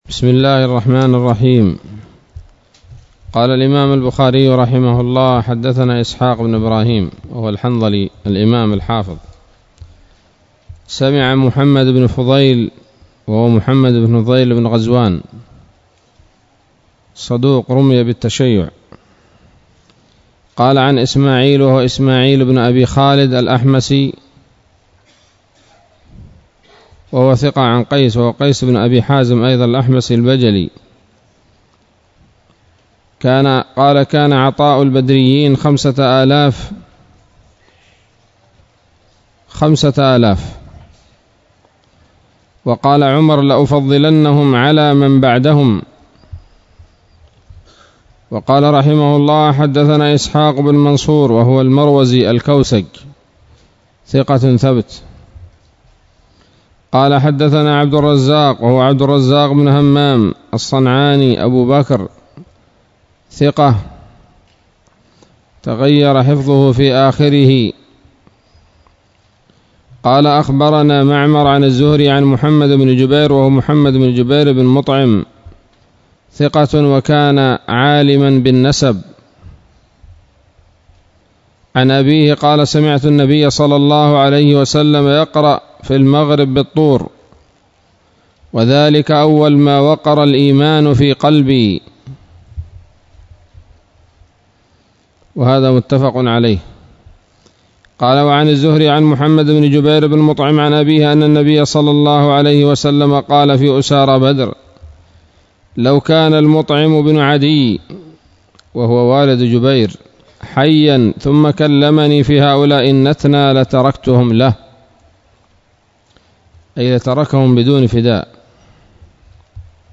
الدرس الثالث والعشرون من كتاب المغازي من صحيح الإمام البخاري